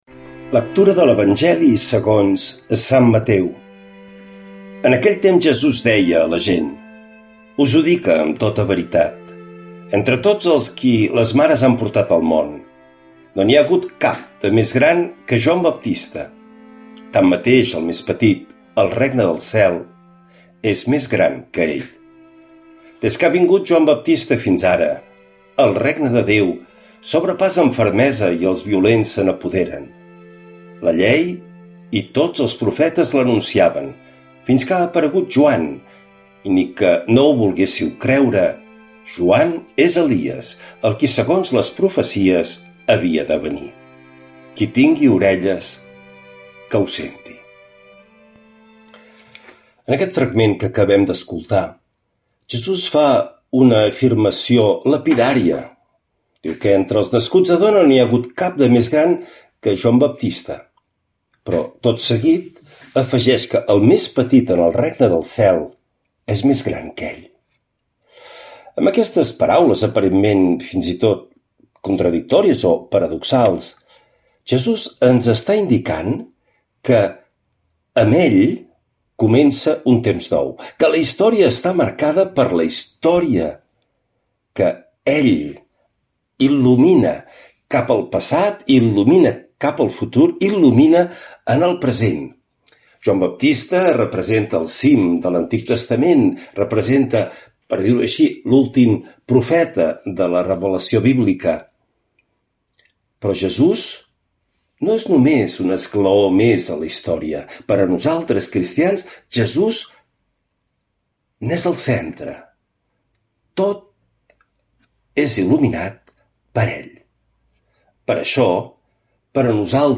L’Evangeli i el comentari de dijous 11 de desembre del 2025.
Lectura de l’evangeli segons sant Mateu